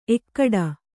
♪ ekkaḍa